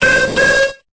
Cri de Xatu dans Pokémon Épée et Bouclier.